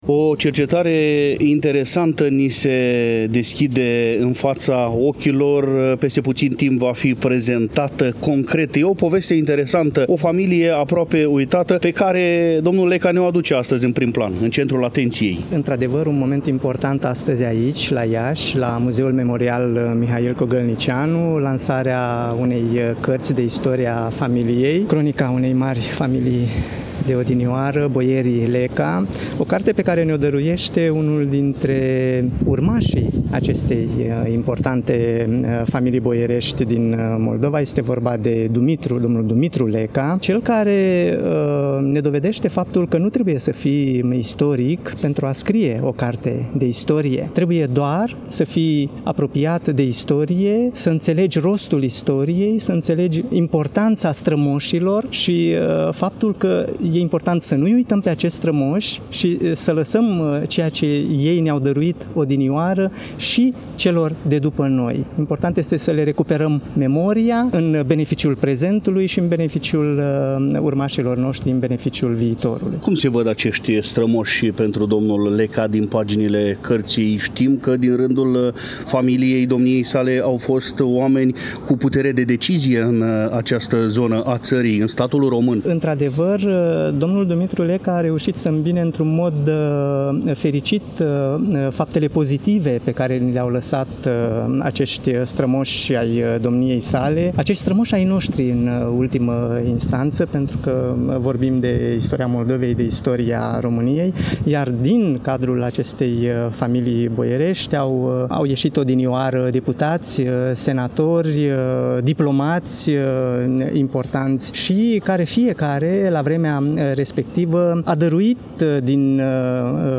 Volumul a fost lansat, la Iași, nu demult, în incinta Muzeului Memorial „Mihail Kogălniceanu” din cadrul Complexului Muzeal Național „Moldova”.